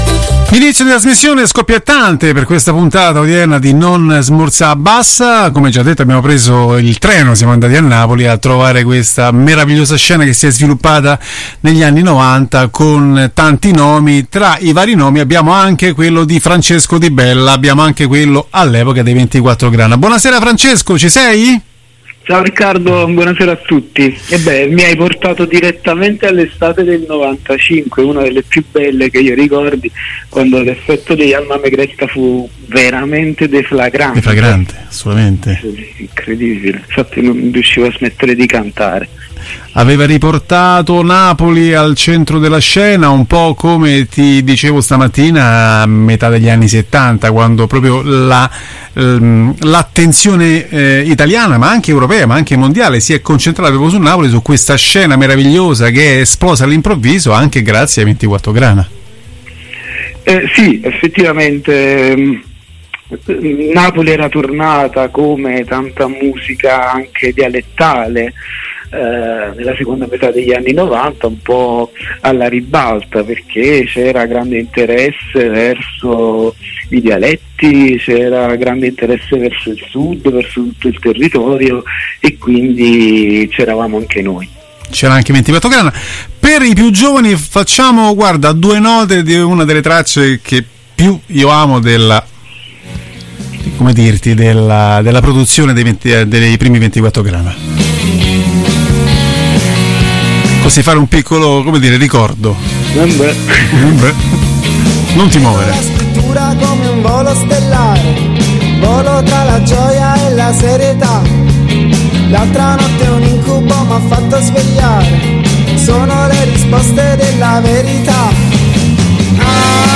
Intervista a Francesco Di Bella